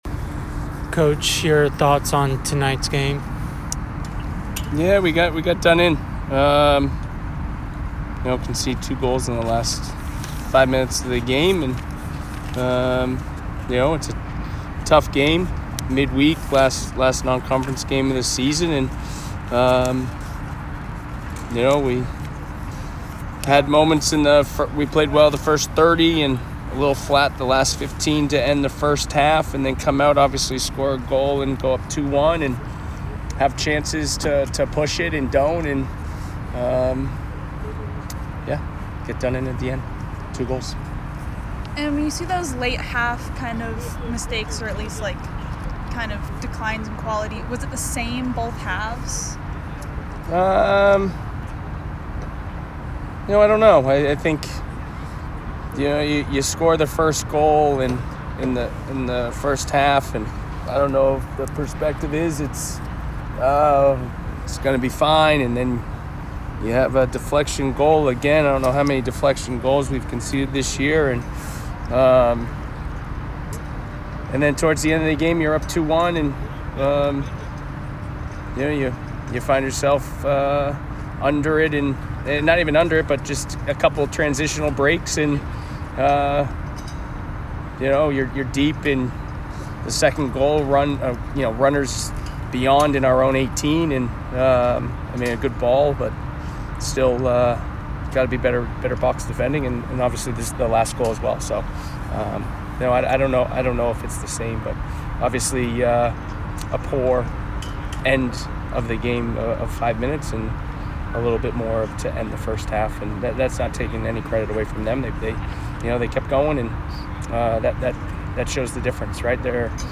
Northeastern Postgame Interview